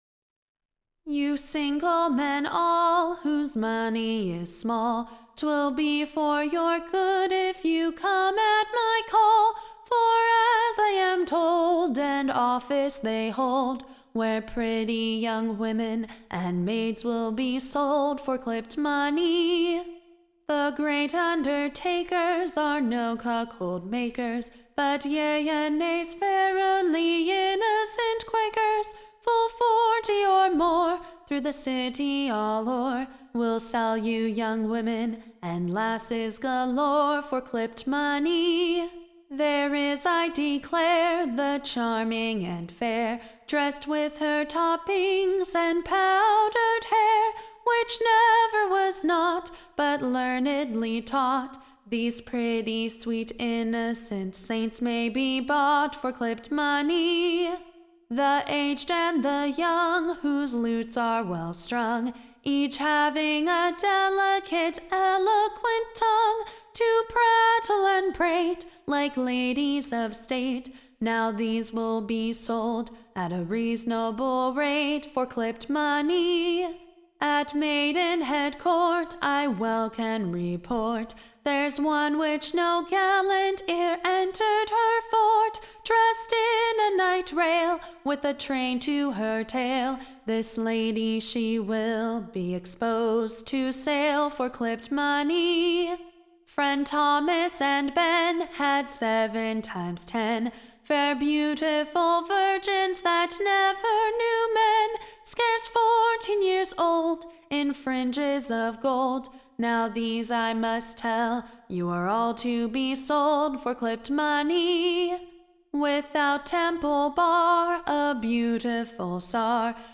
Ballad
Tune Imprint To the Tune of, An Orange, &c. Standard Tune Title With a Fading Media Listen 00 : 00 | 5 : 51 Download P4.234.mp3 (Right click, Save As)